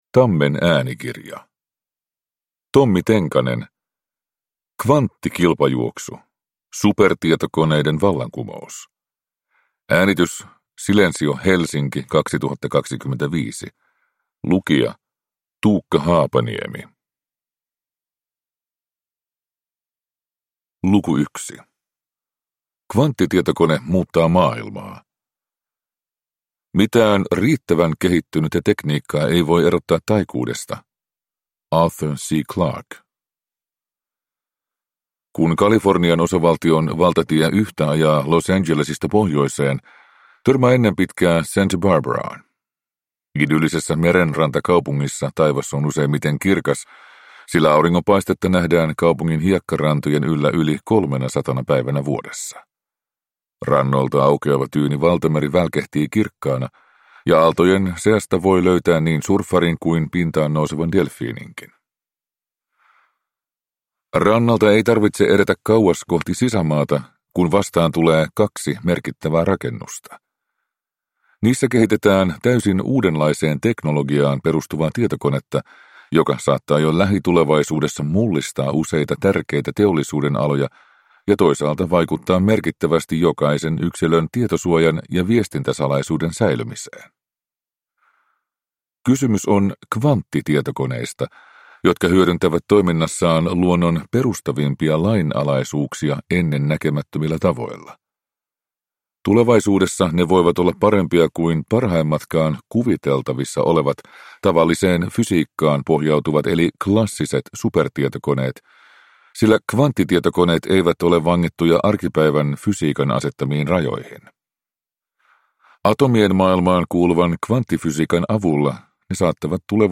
Kvanttikilpajuoksu – Ljudbok